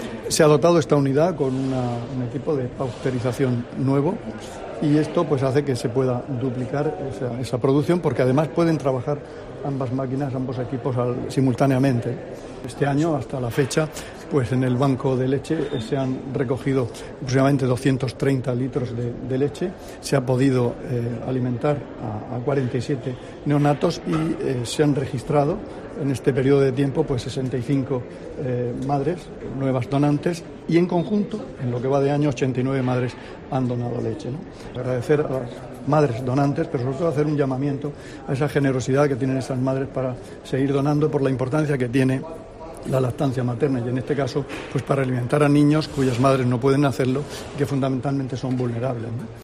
Juan José Pedreño, consejero de Salud